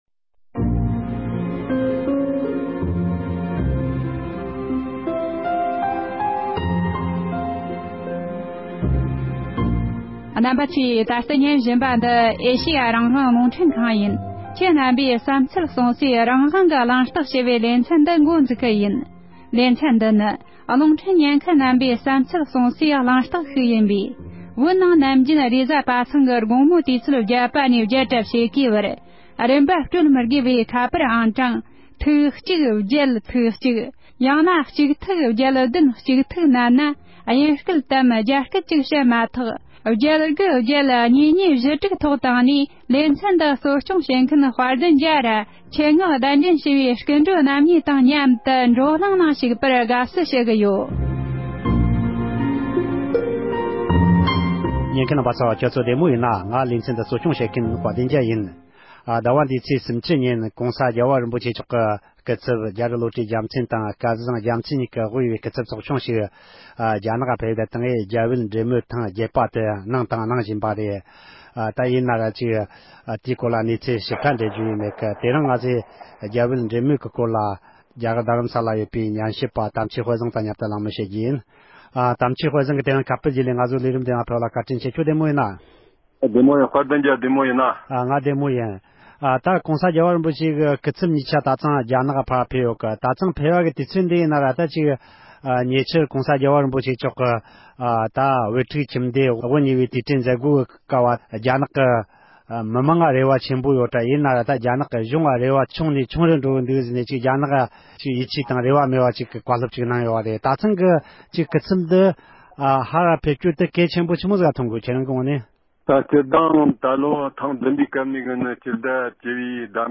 ༸གོང་ས་མཆོག་གི་བཀའ་སློབ།